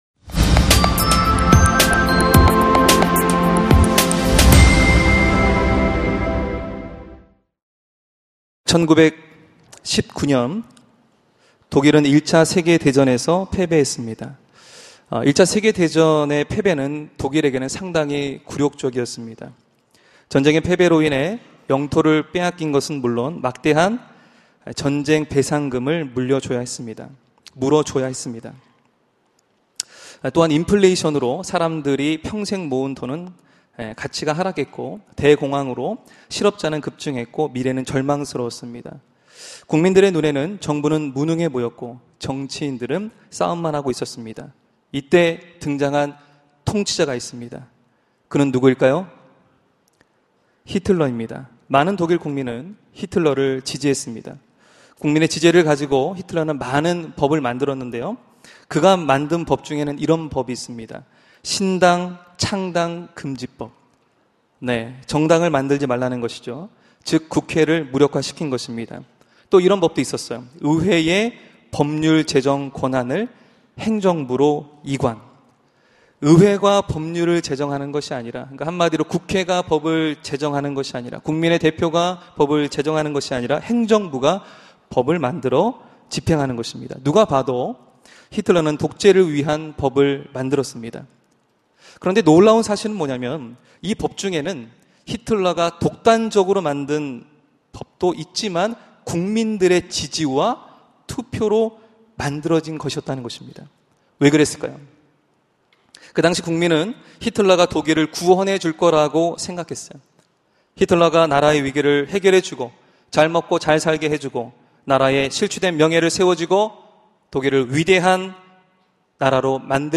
설교 : Christmas Special Worship